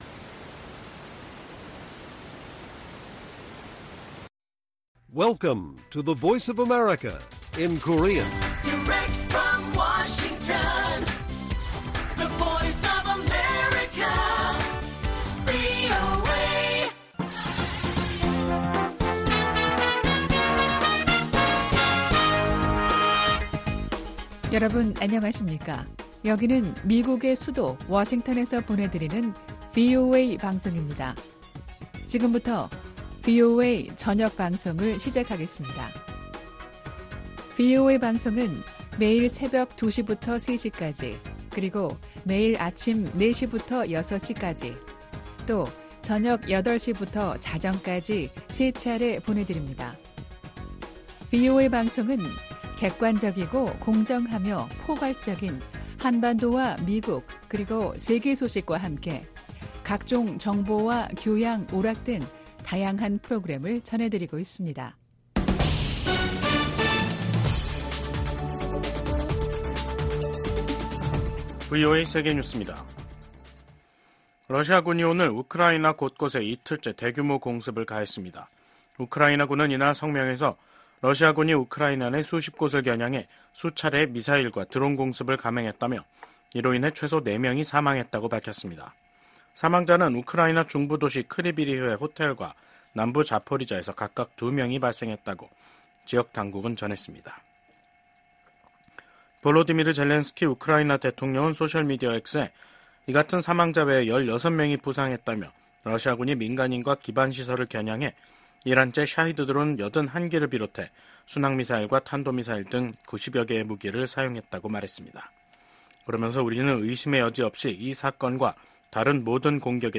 VOA 한국어 간판 뉴스 프로그램 '뉴스 투데이', 2024년 8월 27일 1부 방송입니다. 북한이 영변 핵 시설을 가동하고 평양 인근 핵 시설인 강선 단지를 확장하고 있다고 국제원자력기구(IAEA)가 밝혔습니다. 미국 정부가 한국에 대한 아파치 헬기 판매가 안보 불안정을 증대시킬 것이라는 북한의 주장을 일축했습니다.